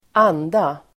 Uttal: [²'an:da]